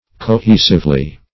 Co*he"sive*ly, adv.